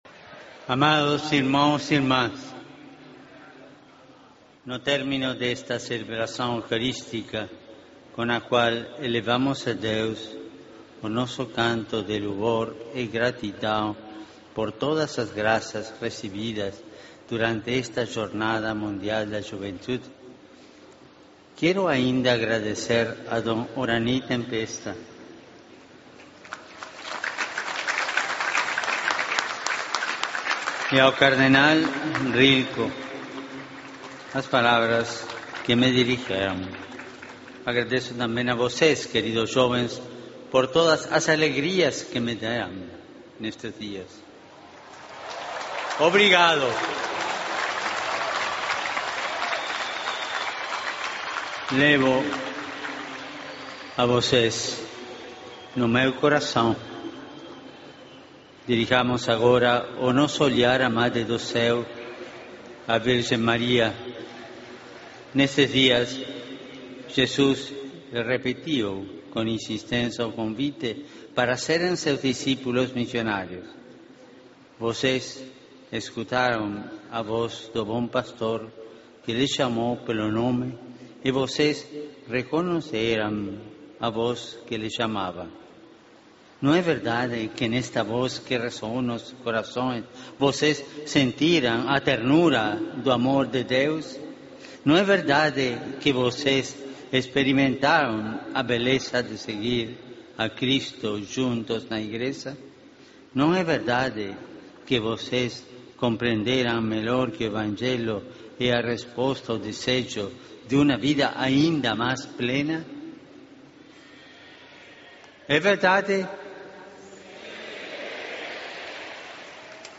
Escucha el rezo del Ángelus del Papa en Río de Janeiro